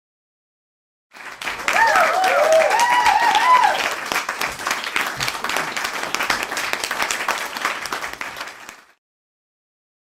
دانلود آهنگ دست زدن 2 از افکت صوتی انسان و موجودات زنده
دانلود صدای دست زدن 2 از ساعد نیوز با لینک مستقیم و کیفیت بالا
برچسب: دانلود آهنگ های افکت صوتی انسان و موجودات زنده دانلود آلبوم صدای دست زدن و تشویق از افکت صوتی انسان و موجودات زنده